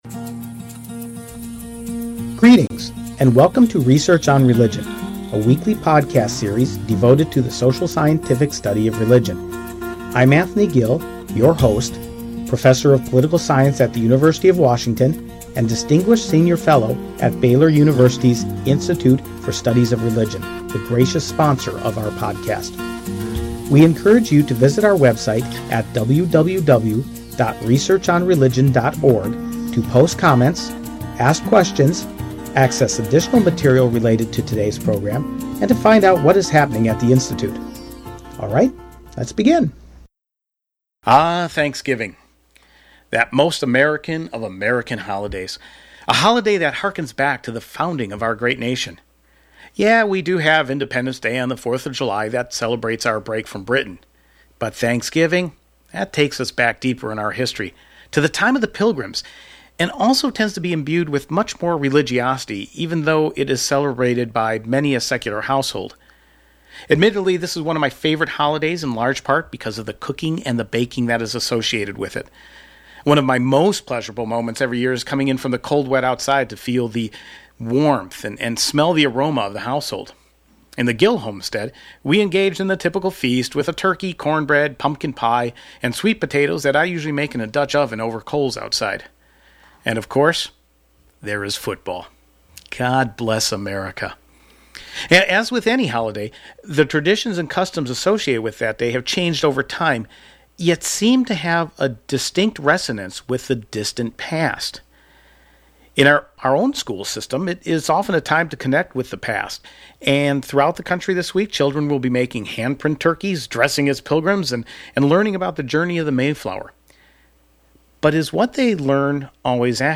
We start our conversation by looking at the topic of “revisionist history.”